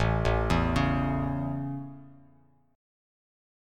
Listen to A strummed